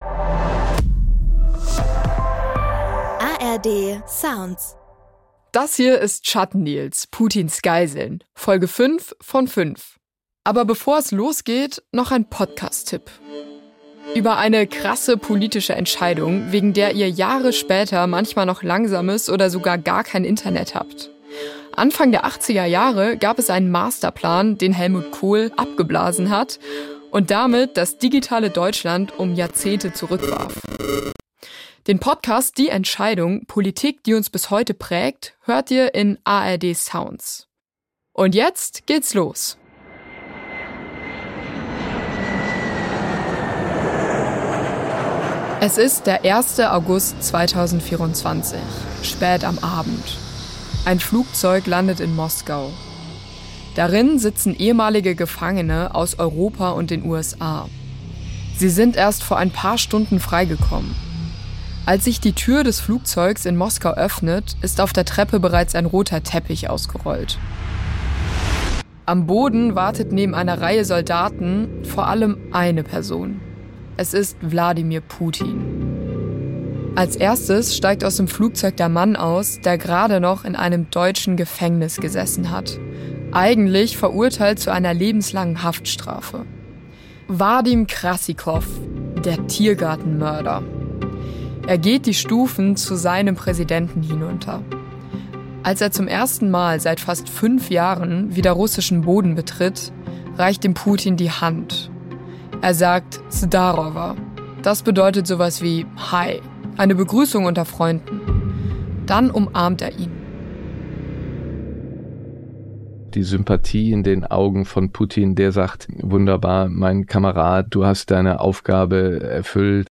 Hinweis zum Sound: In einzelnen Szenen haben wir Hintergrund-Geräusche nachgestellt. Alle Interview-Töne sind real.